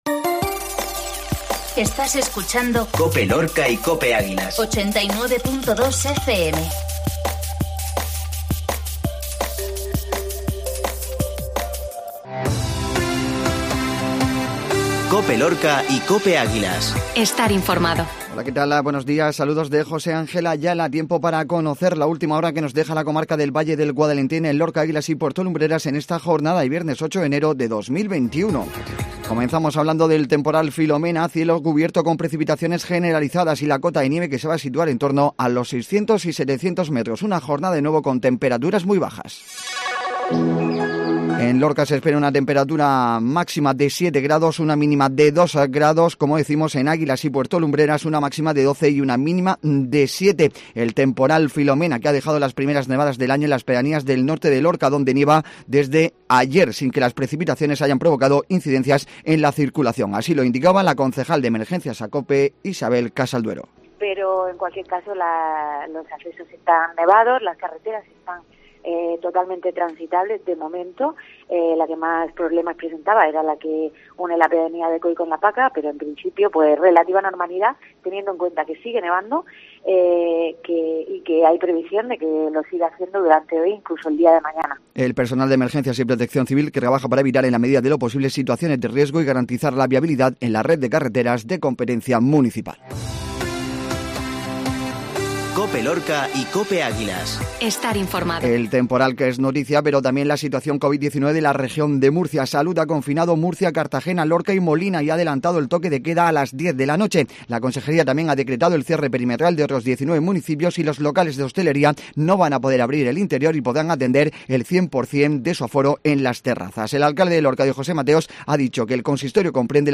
INFORMATIVO MATINAL VIERNES 0801